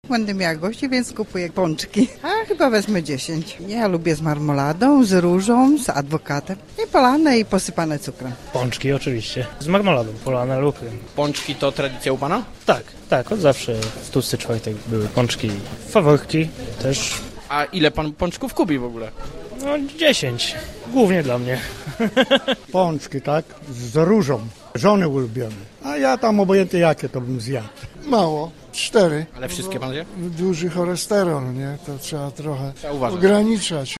Polowe Studio Radia Zielona Góra odwiedziło dzisiaj jedną z cukierni: